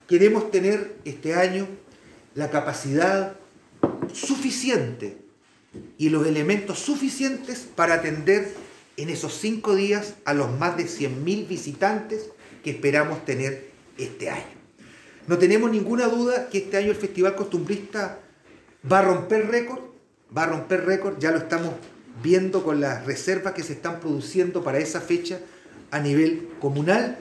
Alcalde-por-festival-costumbrista-2-1-1.mp3